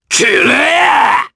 Ezekiel-Vox_Attack4_jp.wav